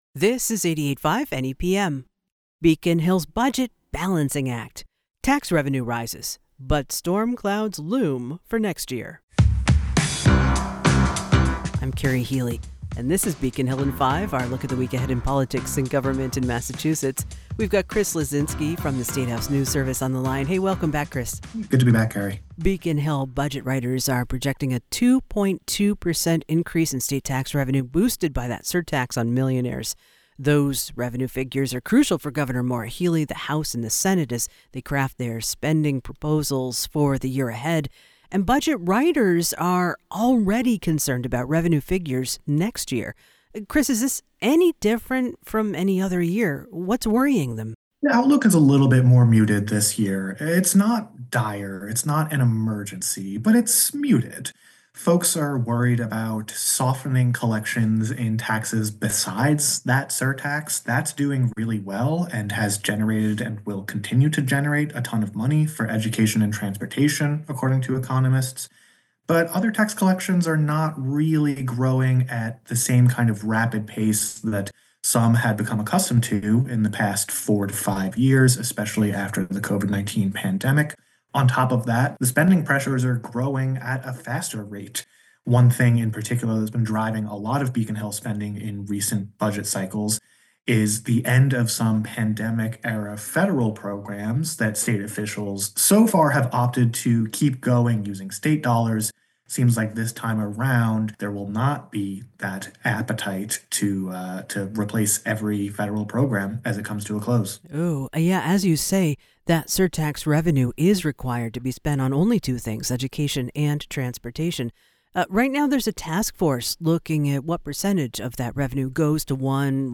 and a journalist from the State House News Service.